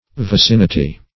Vicinity \Vi*cin"i*ty\ (v[i^]*s[i^]n"[i^]*t[y^]; 277), n. [L.